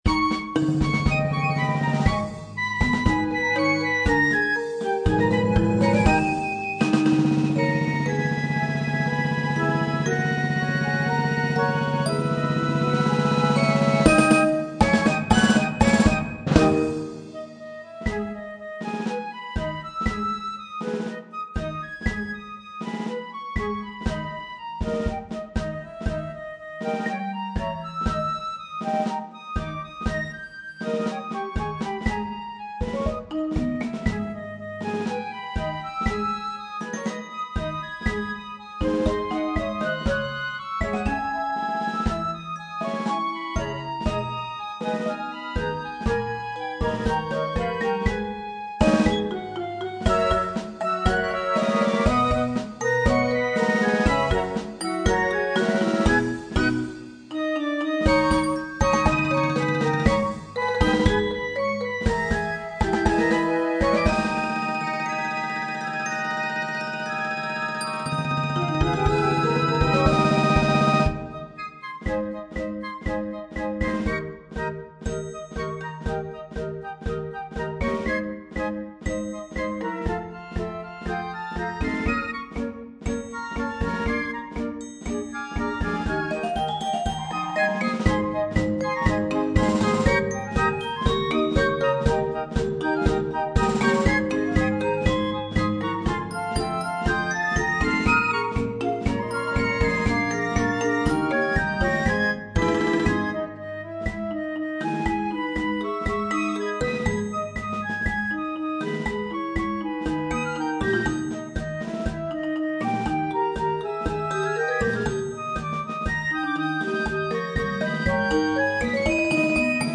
Demo Ces/Fes-Besetzung